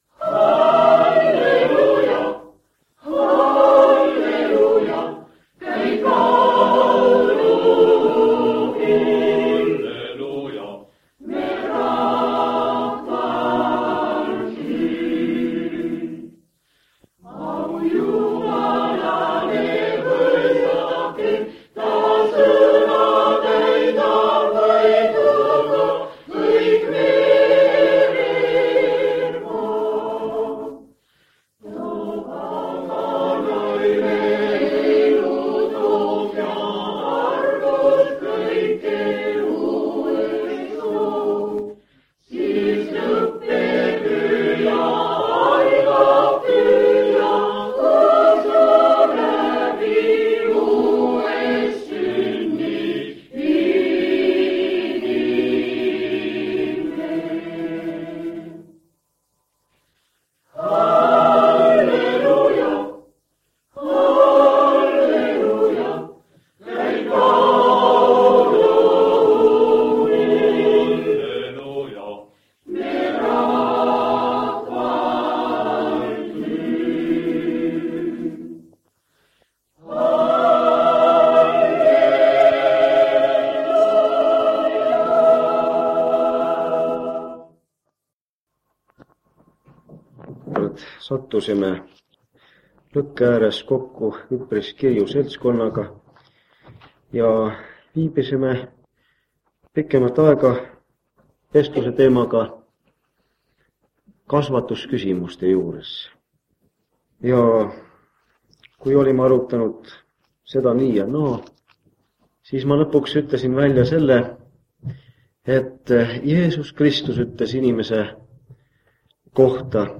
Jutlus 1983 aastast vanalt lintmaki lindilt Rakvere kogudusest.